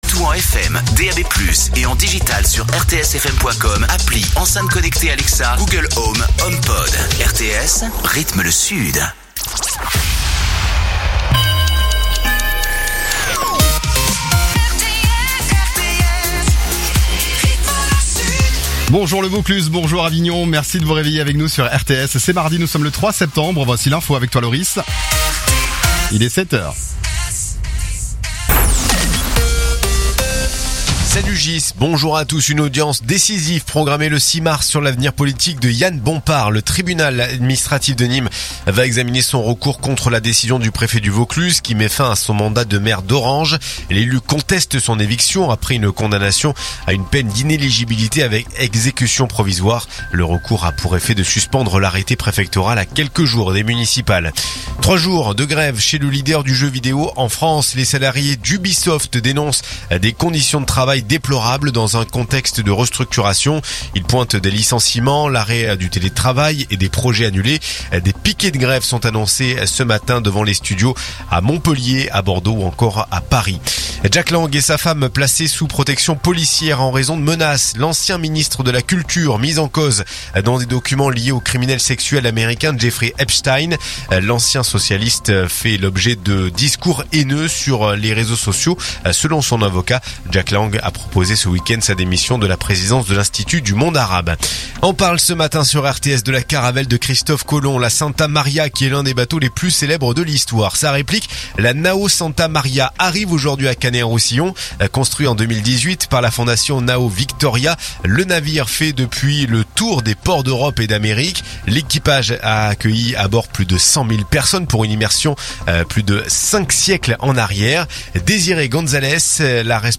RTS : Réécoutez les flash infos et les différentes chroniques de votre radio⬦